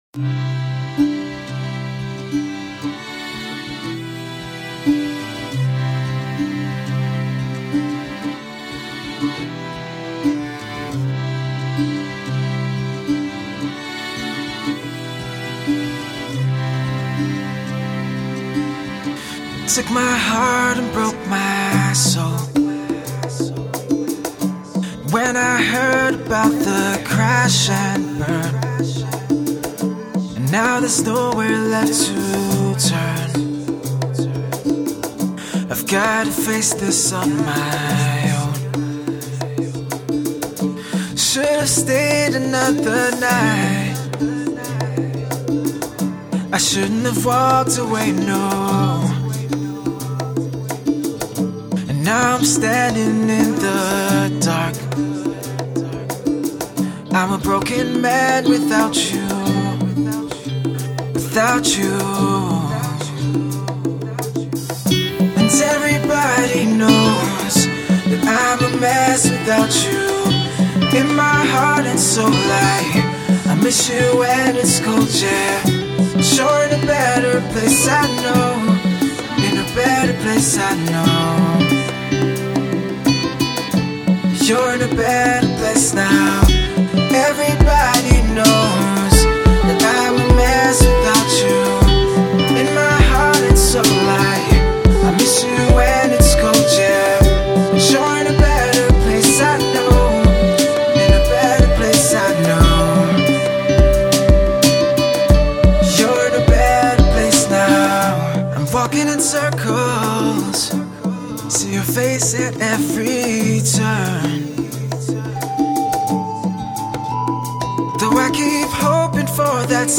Deep, soulful and heart-string-tugging